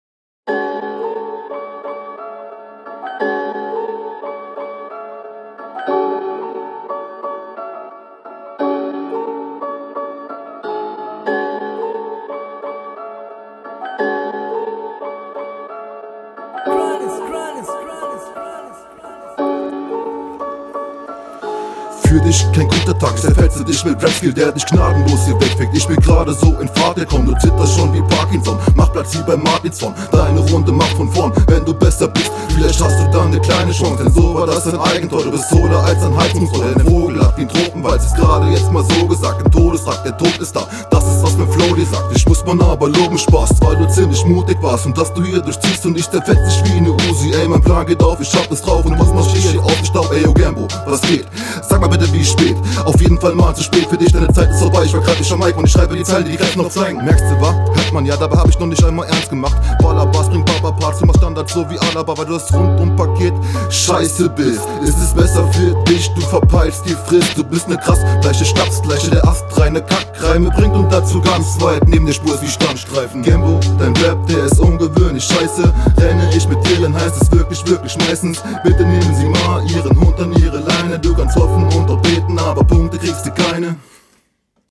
Auweia der Einstieg war unsauber.. bist hier nicht so präsent wie in der RR2.
Flow: Der Flow wirkt hier leider sehr angestrengt irgendwie zu gewollt.